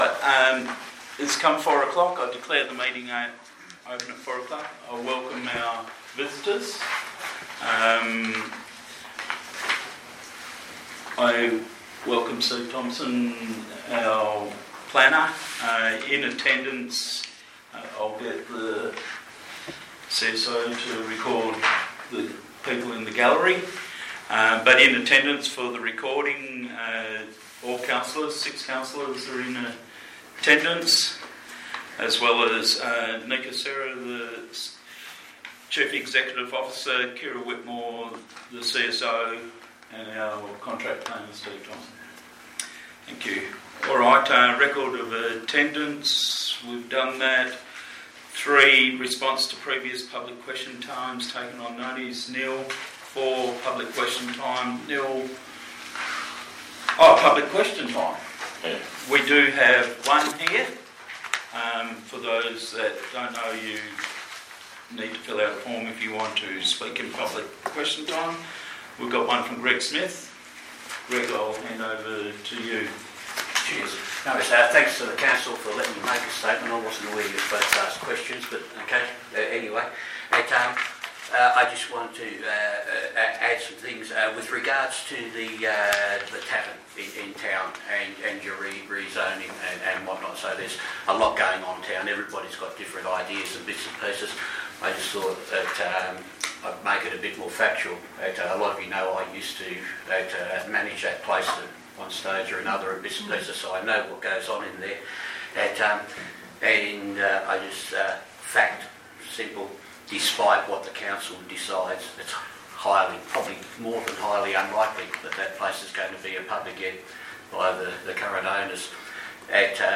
19 August 2025 - Ordinary Council Meeting